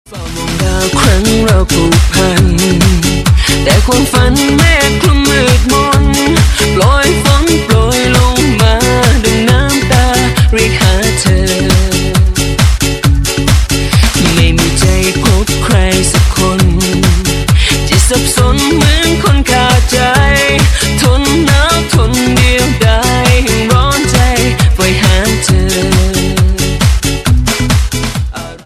DJ铃声
泰国舞曲